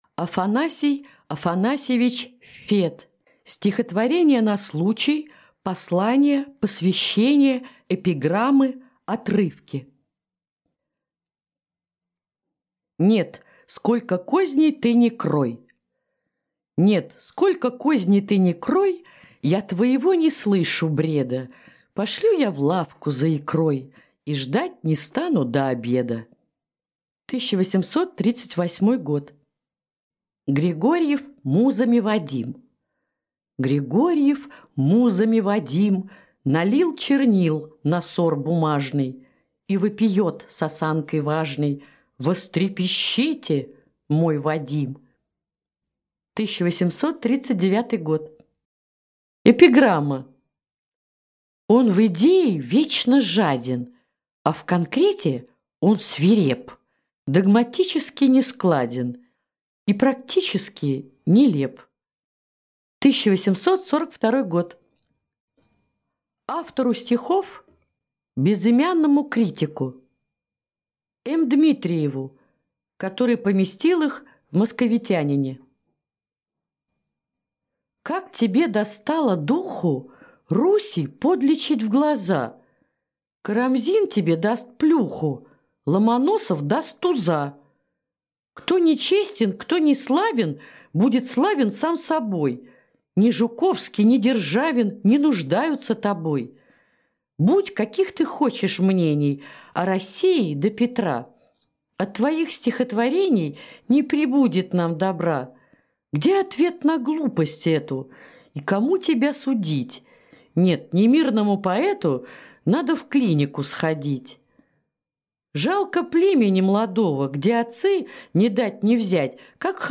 Аудиокнига Стихотворения на случай, послания, посвящения, эпиграммы, отрывки | Библиотека аудиокниг